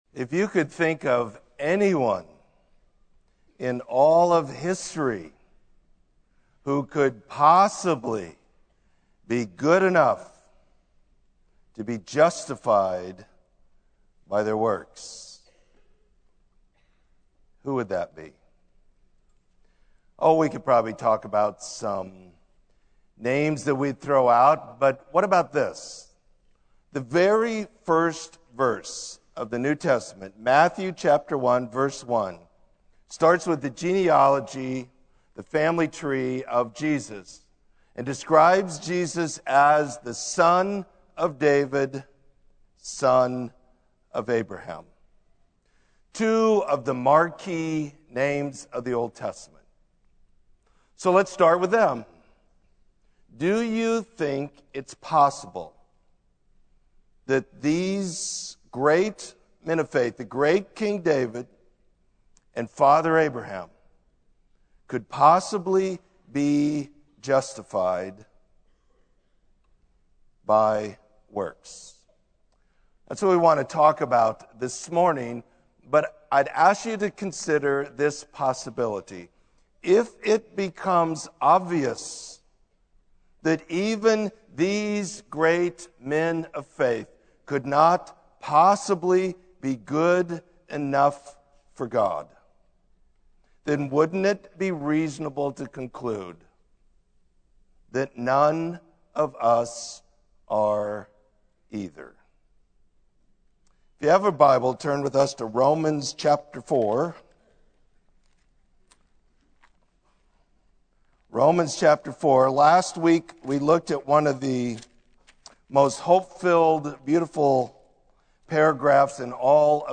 Sermon: Like Abraham